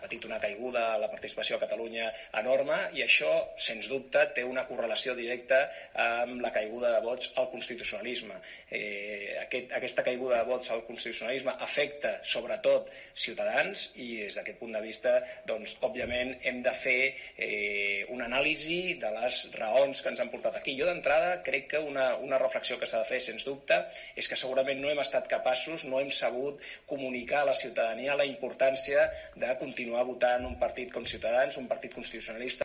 El secretario de comunicación de Ciutadans, Nacho Martín Blanco en rueda de prensa